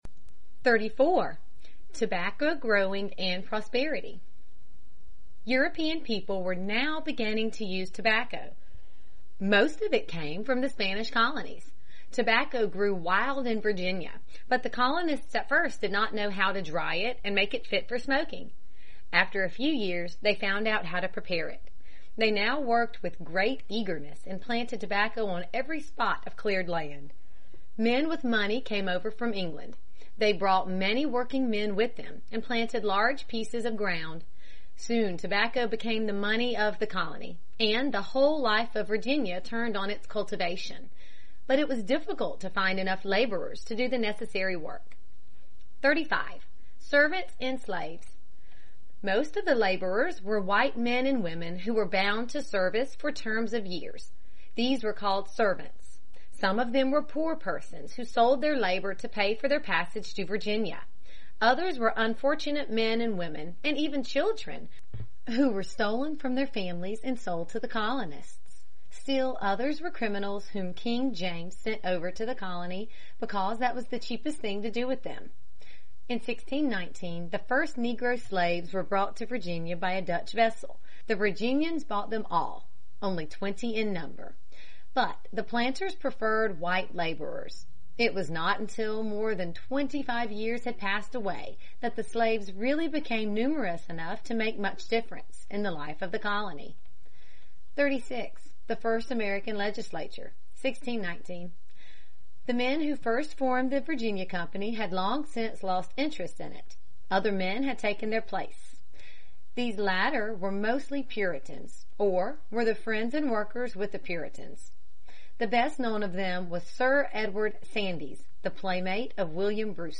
在线英语听力室美国学生历史 第12期:弗吉尼亚和马里兰(2)的听力文件下载,这套书是一本很好的英语读本，采用双语形式，配合英文朗读，对提升英语水平一定更有帮助。